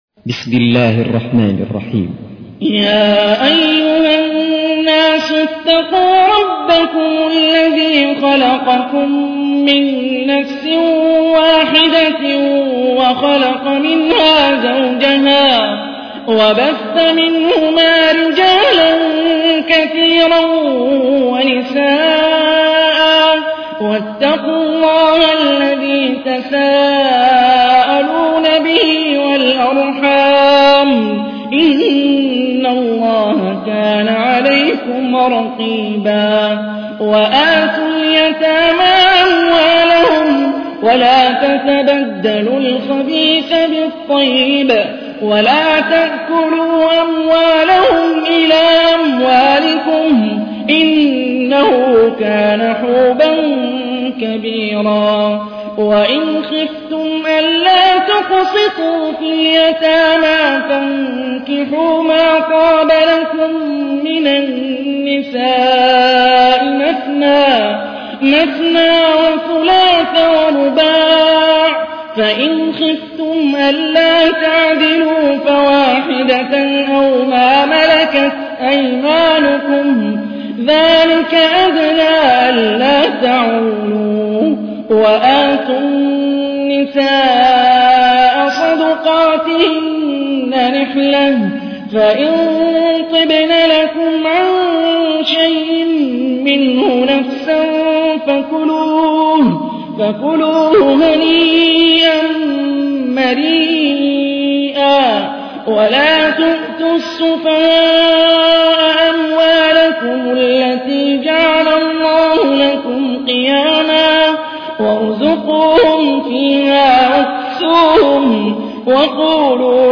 تحميل : 4. سورة النساء / القارئ هاني الرفاعي / القرآن الكريم / موقع يا حسين